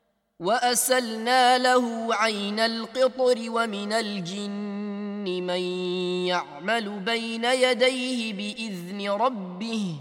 bokstaven rā' (راء ـ ر) uttalas lātt